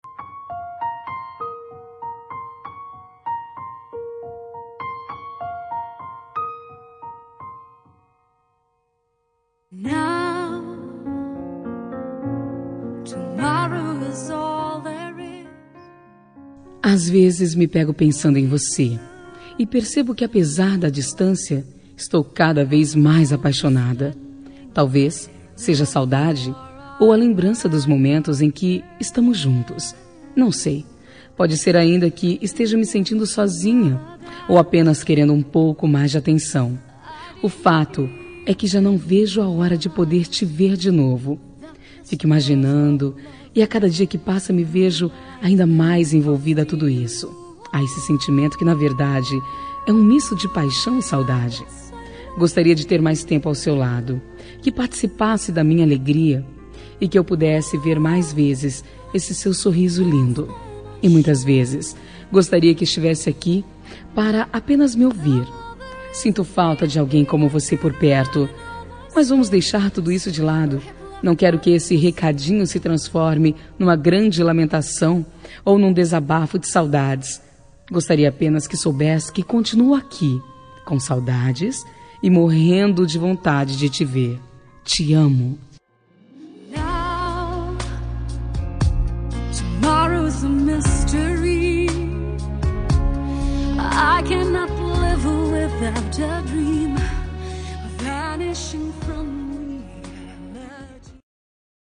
Telemensagem Romântica Distante – Voz Feminino – Cód: 773
773-romantica-distante-fem.m4a